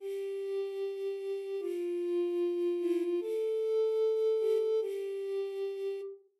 描述：合成器
Tag: 150 bpm Trap Loops Strings Loops 1.08 MB wav Key : C